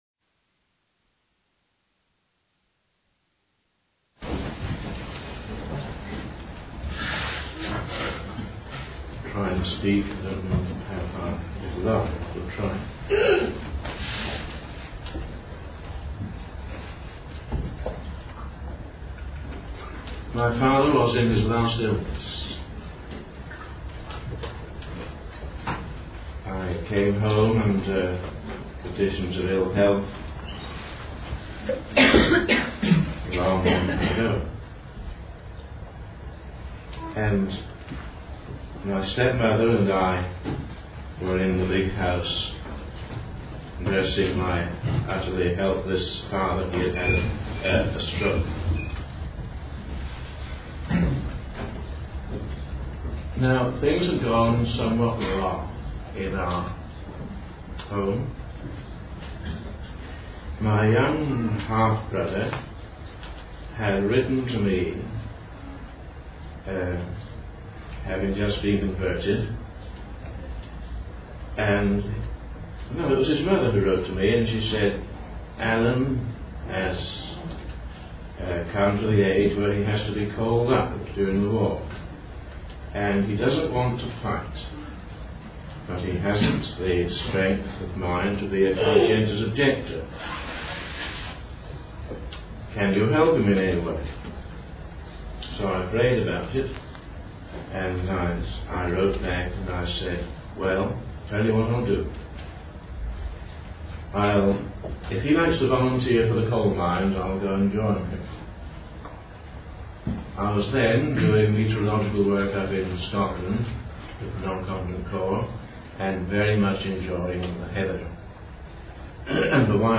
In this sermon, the speaker expresses a desire to go deeper in understanding the mystery and depth of Christ's love and knowledge.